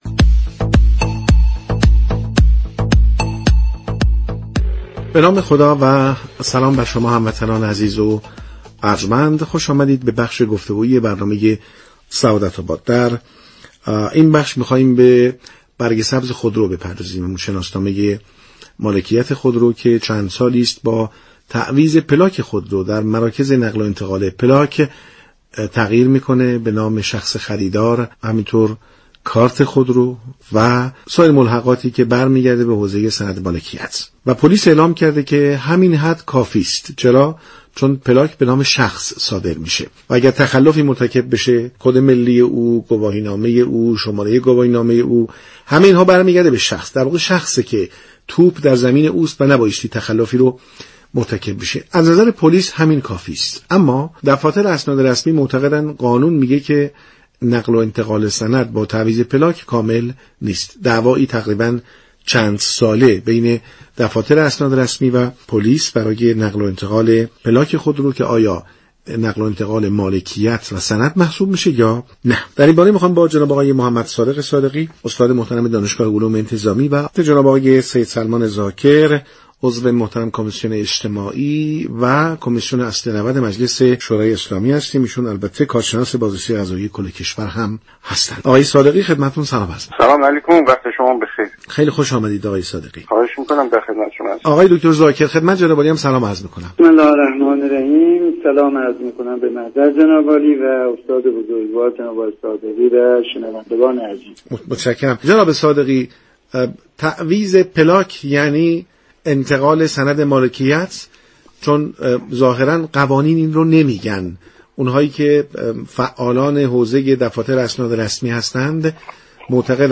میزگرد برنامه سعادت آباد 20 دیماه با موضوع آیا برگ سبز مالكیت خودرو به منزله سند رسمی تلقی می شود؟ با حضور كارشناسان حقوقی و انتظامی از رادیو تهران پخش شد.